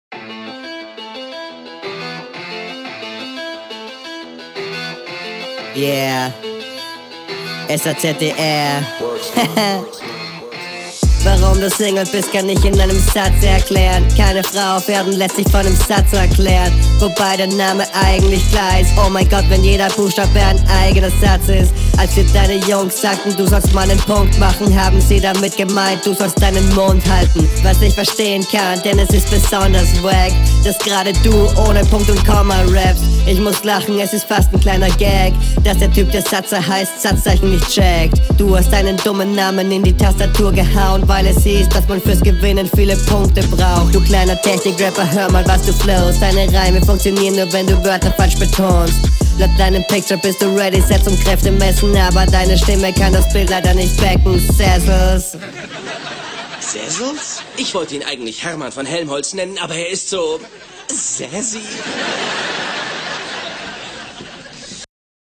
Punkt machen Zeile highlight zu geil Technik und flow gut Pitch ist arsch.